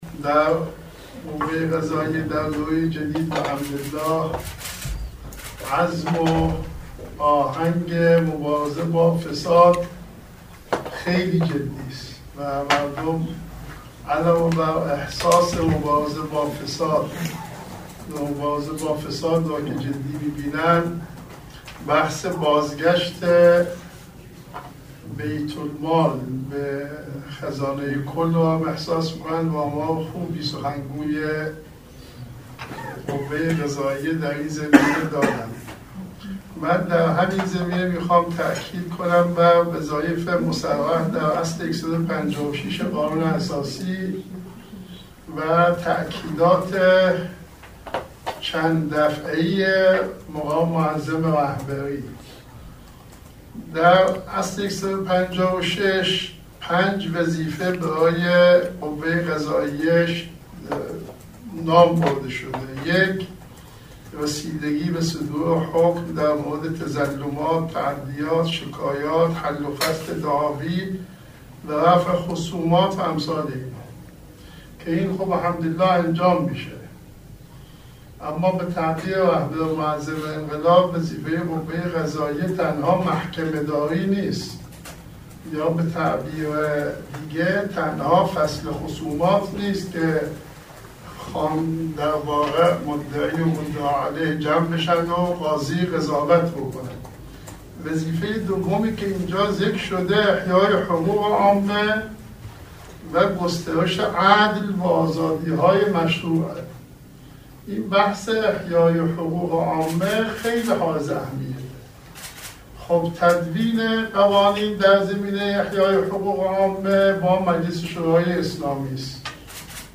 به گزارش خبرنگار سیاسی خبرگزاری رسا، آیت الله عباس کعبی عضو جامعه مدرسین حوزه علمیه قم عصر امروز در نشست هفتگی جامعه مدرسین قم با تقدیر از دستگاه قضا برای مبارزه با مفاسد اقتصادی گفت: در دوره جدید قوه قضاییه عزم و مبارزه با فساد بسیار جدی است و شاهد بازگشت بسیاری از اموال بیت اموال به خزانه هستیم.